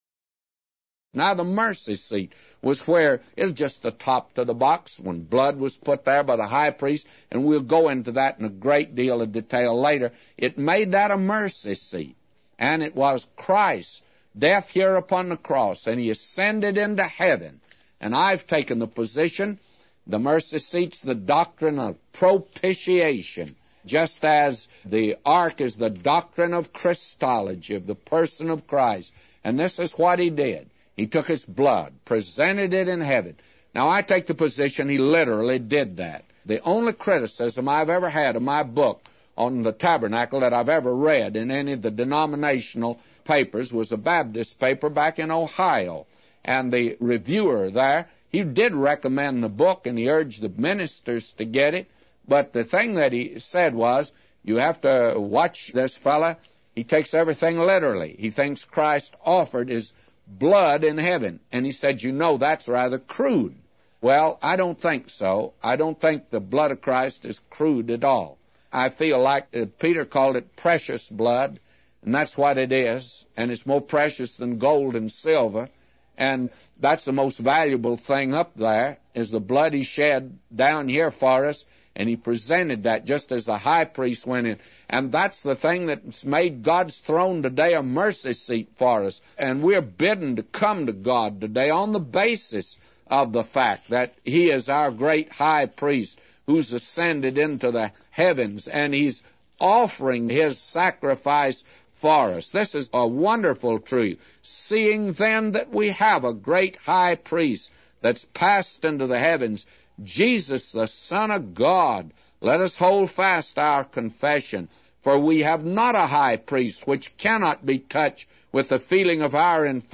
A Commentary By J Vernon MCgee For Exodus 25:22-999